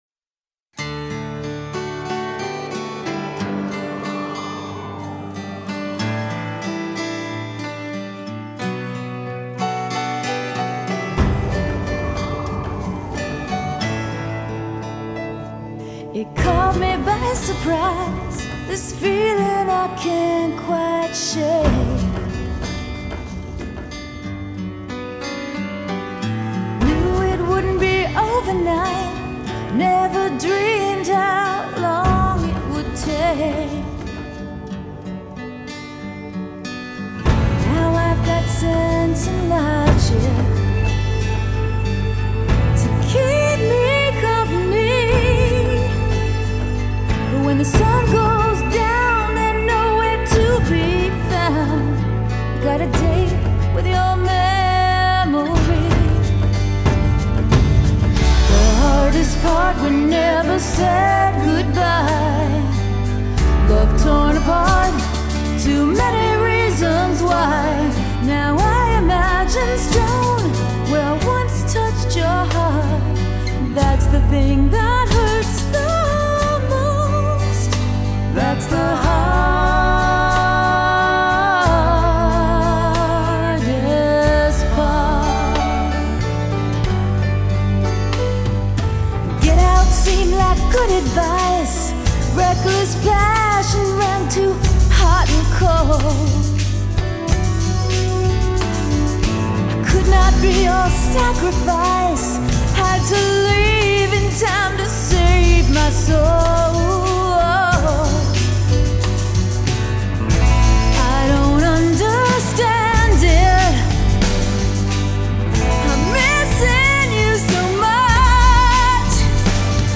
piano accompaniment
recorded in Nashville